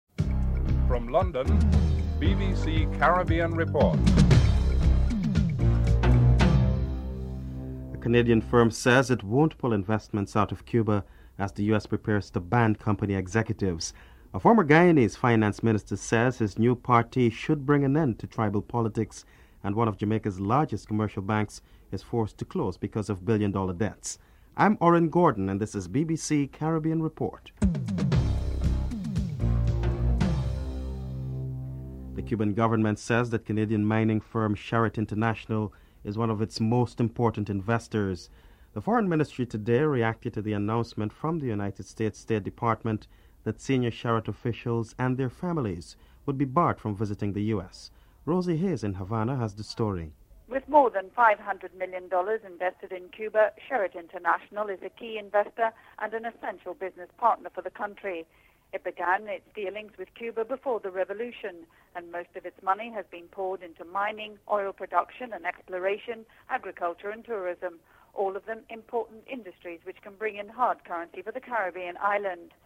1. Headlines (00:00-00:31)
Sir Garfield Sobers is interviewed (12:32-15:21)